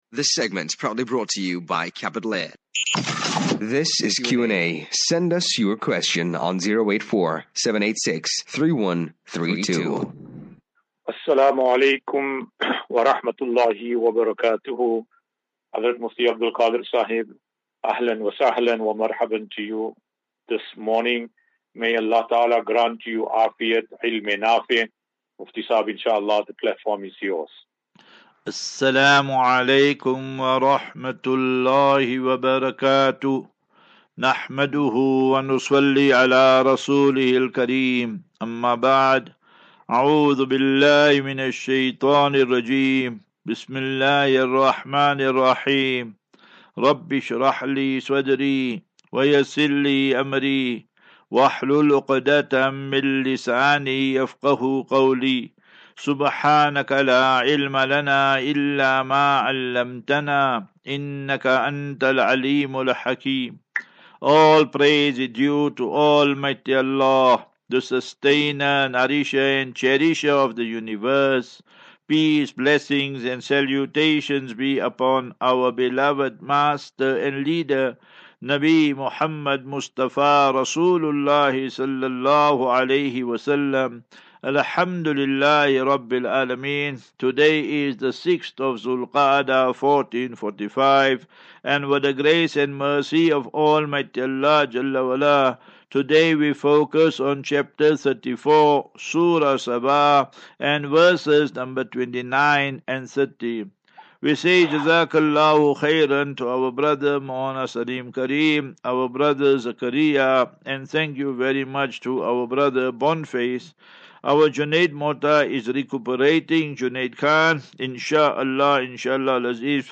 15 May 15 May 24-Assafinatu Illal - Jannah. QnA